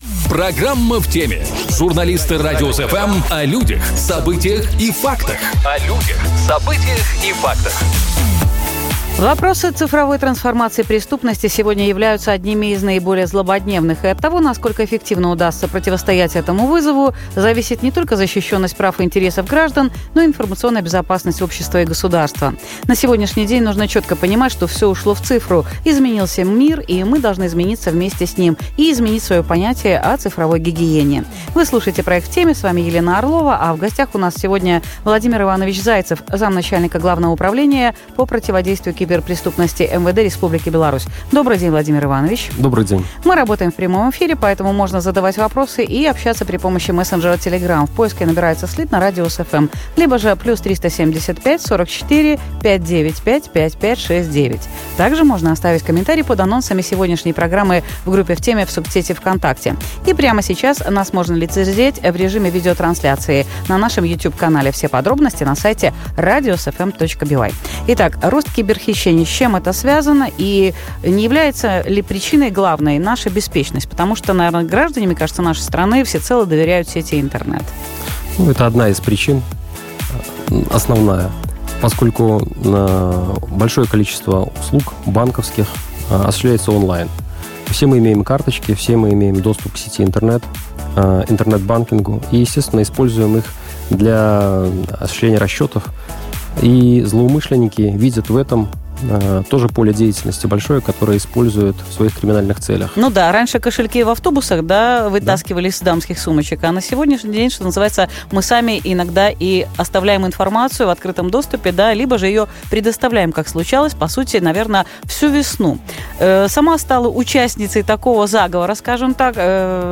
Что такое кардинг, вишинг, спаминг, крекинг, фарминг, ньюкинг, хакинг? Где и как правильно хранить пароли? На эти вопросы отвечает заместитель начальника главного управления по противодействию киберпреступности МВД Владимир Зайцев.